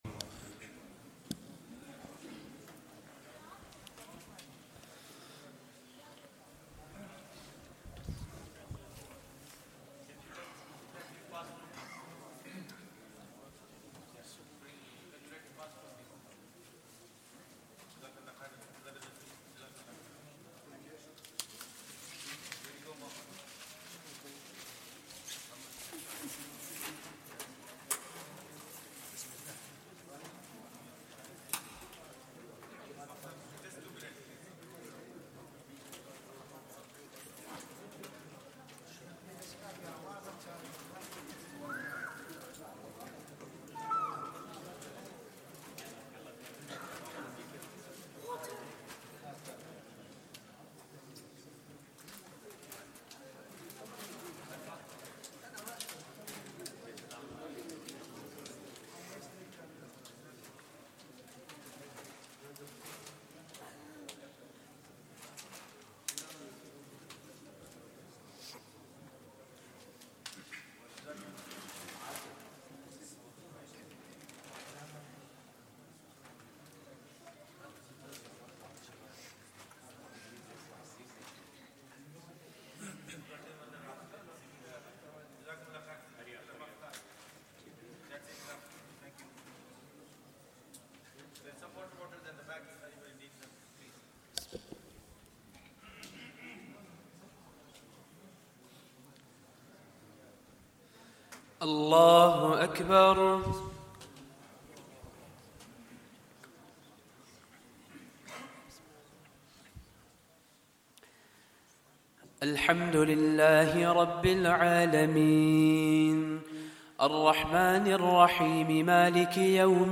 Day 25 - Taraweeh 1444 - Recital
Day 25 - Taraweeh 1444 - Recital Masjid Omar, Glasgow Recording Date: Sat, 15 Apr 2023 Recording Time: 22:18:17 BST Download Right click on the download button and select "Save Link As" to download.